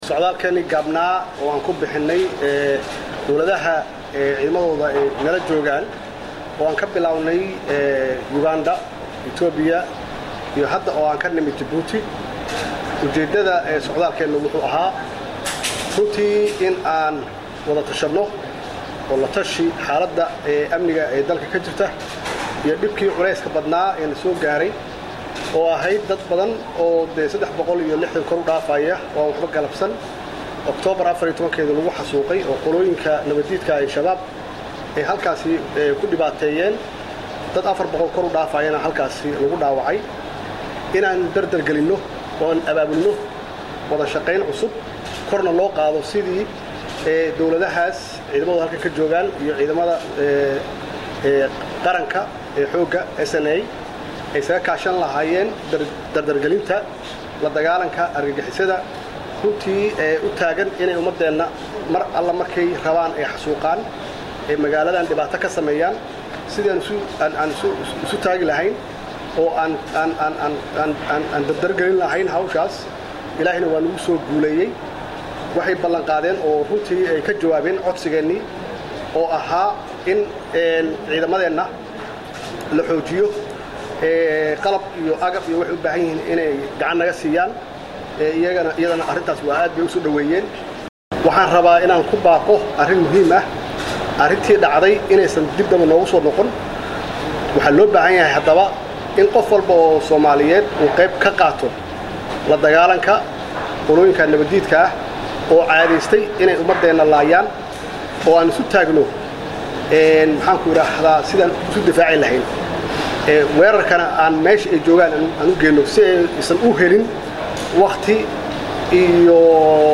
Dhageyso Codka: Madaxweyne Farmaajo oo Sharaxaya Safaradii uu ku tagay Wadamada Deriska | Goobsan Media Inc